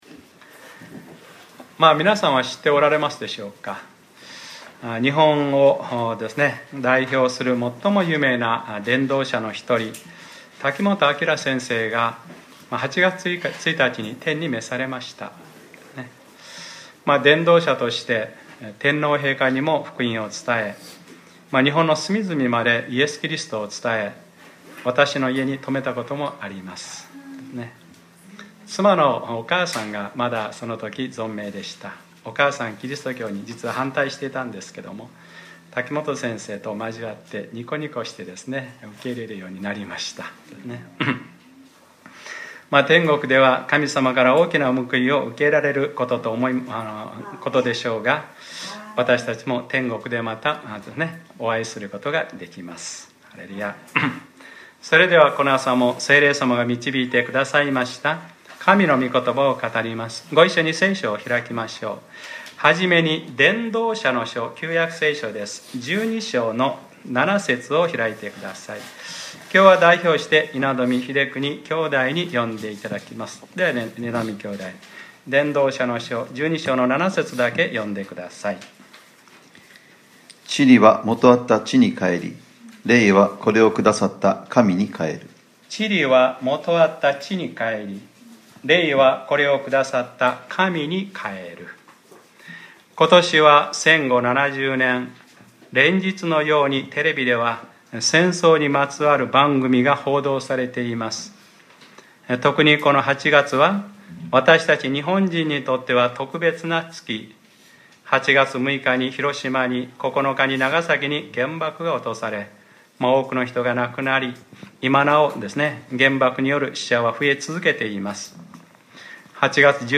2015年08月16日（日）礼拝説教 『家系の呪い』